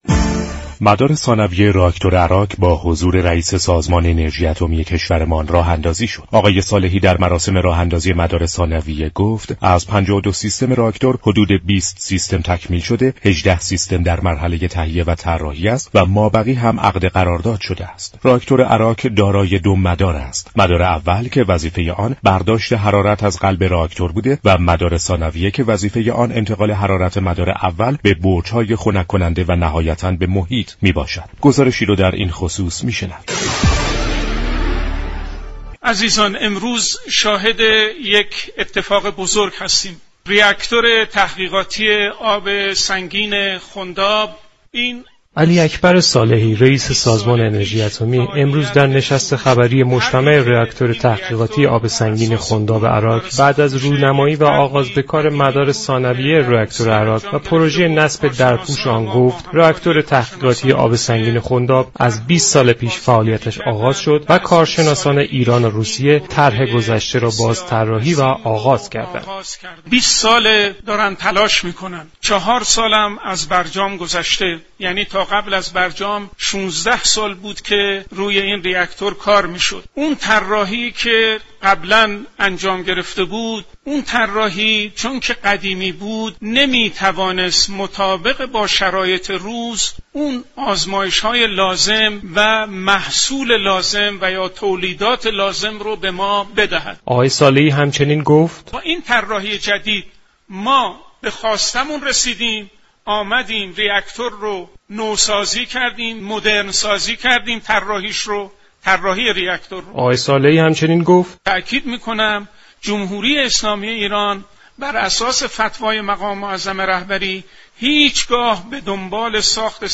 كارشناس مسائل بین الملل در گفت و گو با رادیو ایران